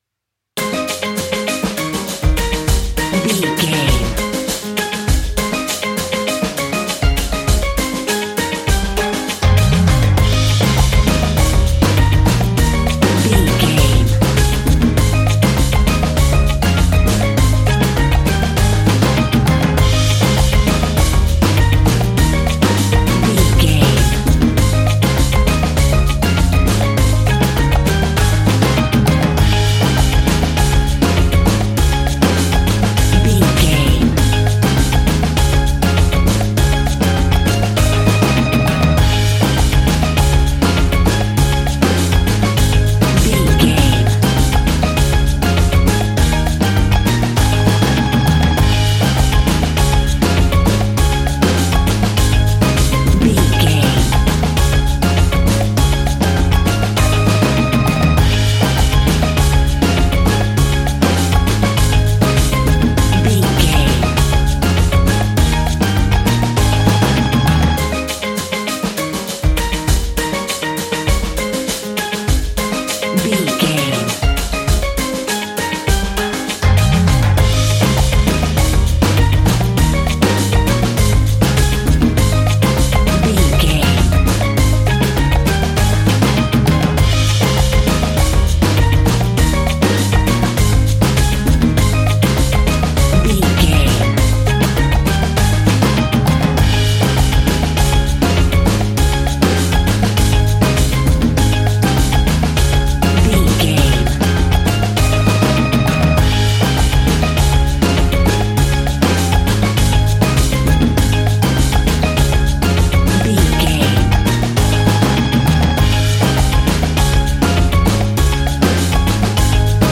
Ionian/Major
A♭
steelpan
drums
percussion
bass
brass
guitar